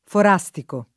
forastico [ for #S tiko ]